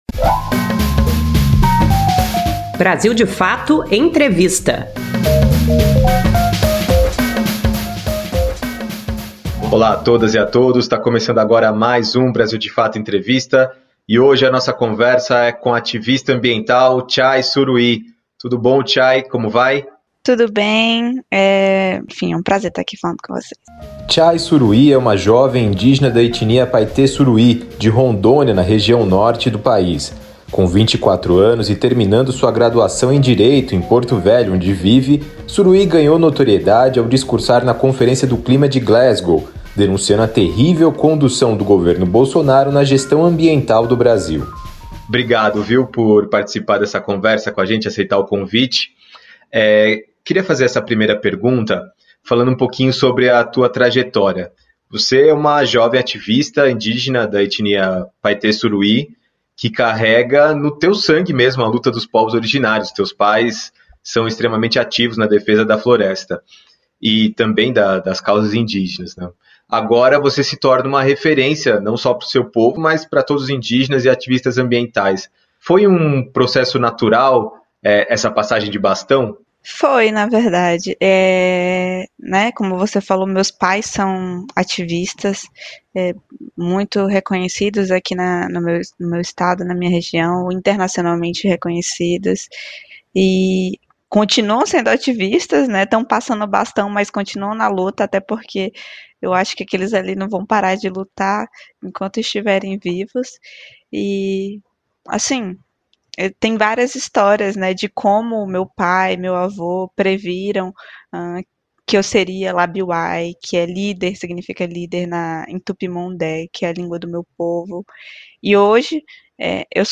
BdF Entrevista